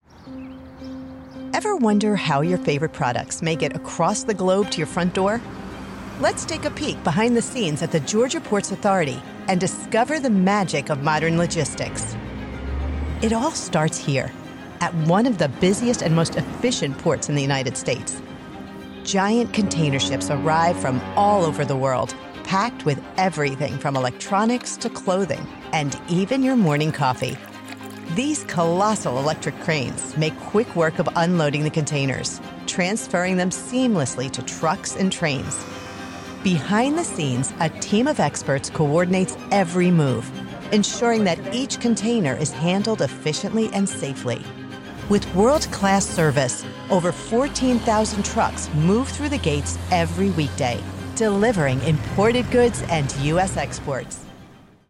Glaxo Smith Kline Voice Over Commercial Actor + Voice Over Jobs
Slightly darker and velvety with an open tip and a strong speech definition.
0627Port_ExplainerSHORT.mp3